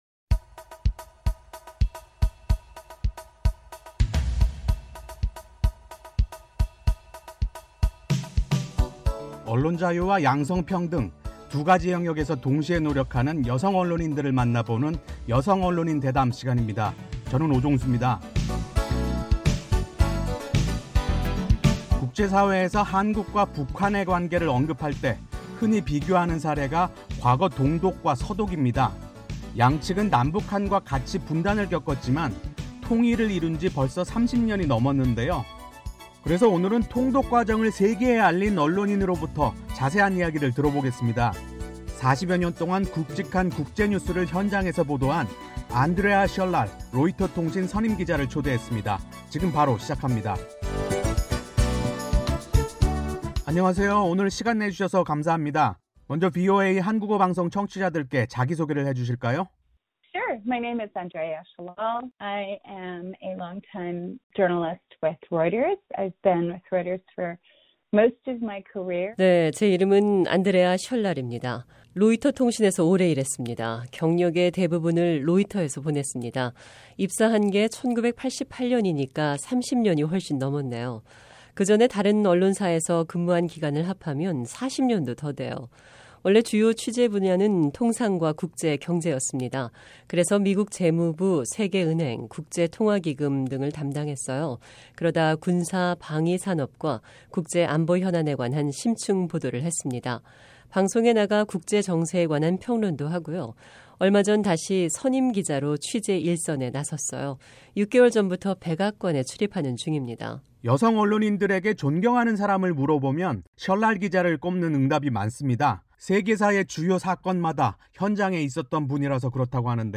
[여성 언론인 대담]